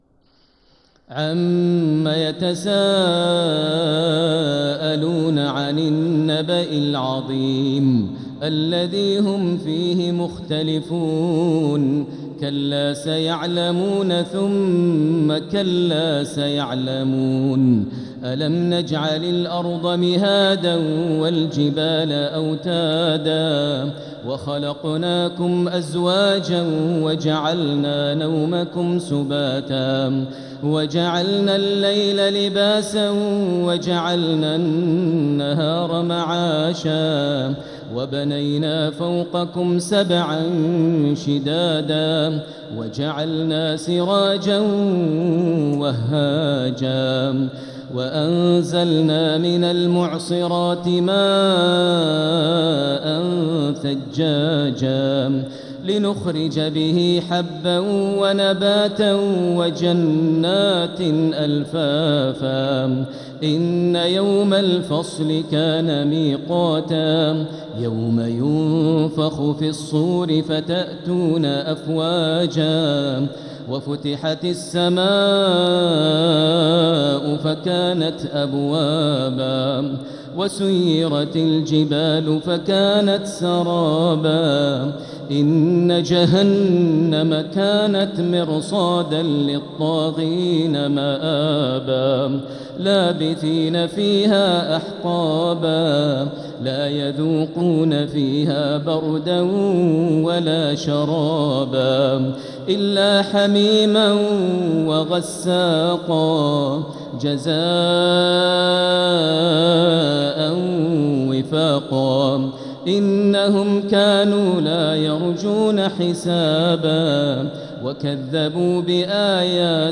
سورة النبأ | مصحف تراويح الحرم المكي عام 1446هـ > مصحف تراويح الحرم المكي عام 1446هـ > المصحف - تلاوات الحرمين